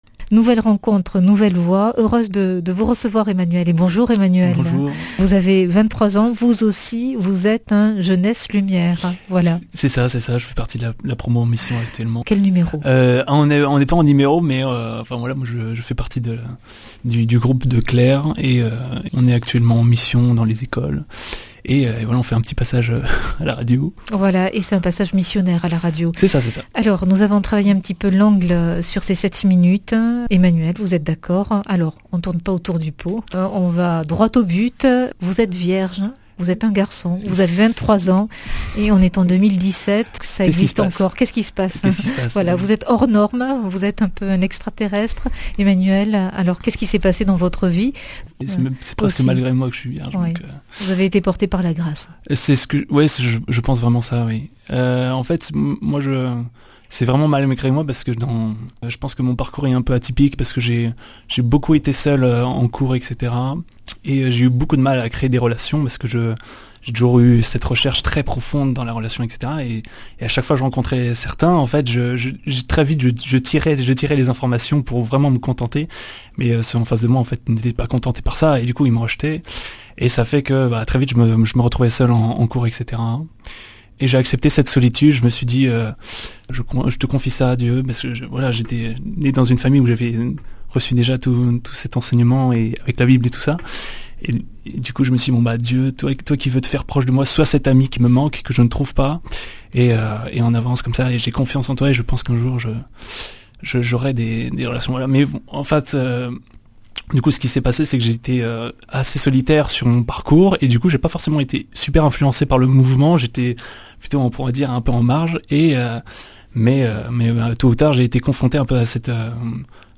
Speech
Une émission présentée par